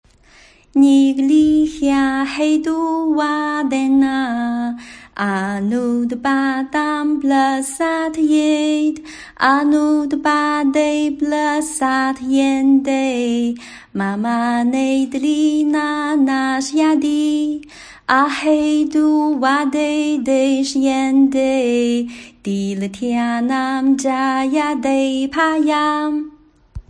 楞伽经3.91念诵.mp3